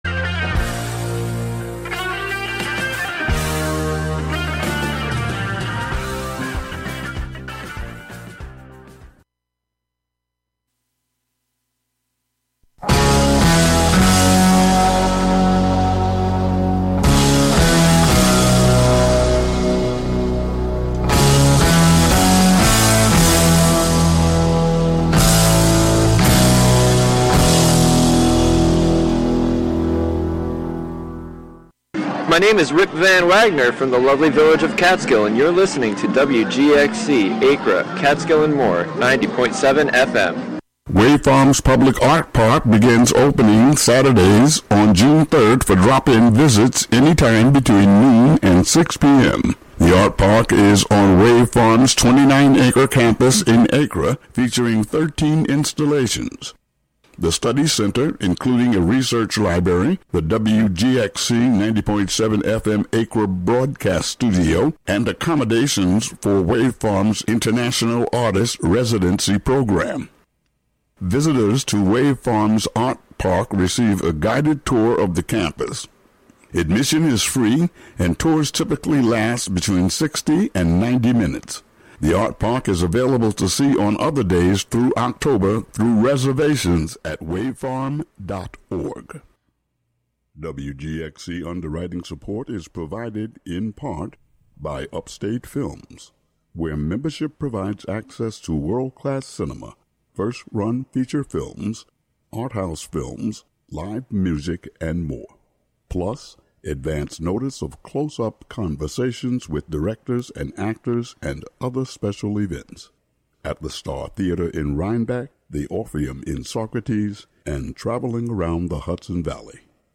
12pm An upbeat music show featuring the American songbo...
jazz
live